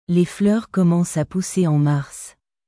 Les fleurs commencent à pousser en marsレ フルール コマォンス ア プッセェ オン マァルス